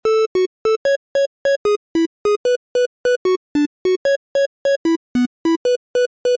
Lead (Before)